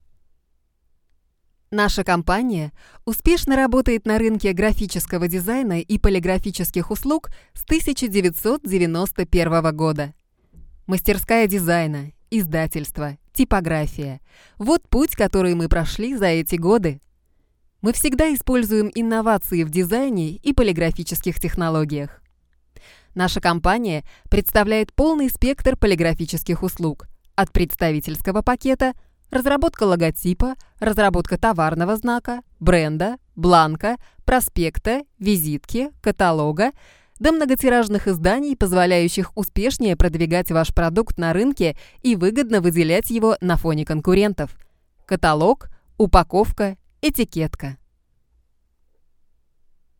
Sprechprobe: Werbung (Muttersprache):
I have my own studio based in NYC. Can do Child, Young Female, Middle Age Female, Senior Female timbre of voice.